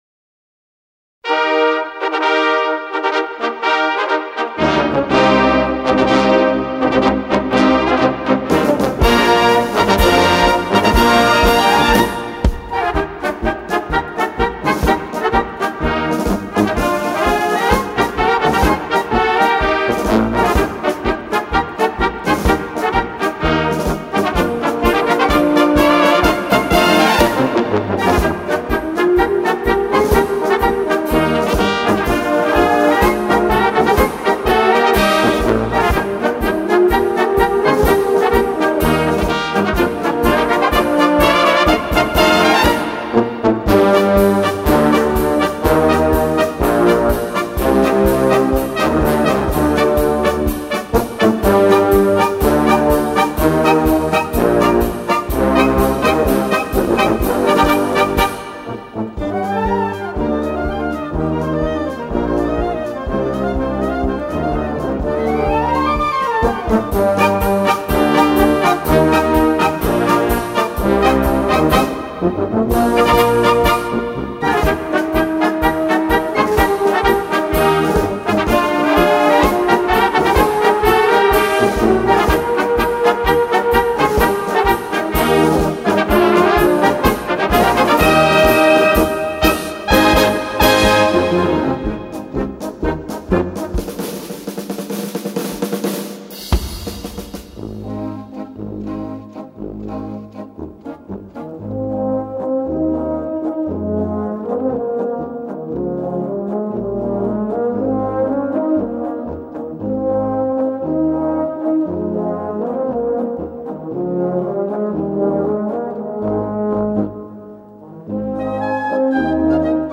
Gattung: Kleine Besetzung
Besetzung: Kleine Blasmusik-Besetzung
Ein Konzertmarsch der “Extraklasse”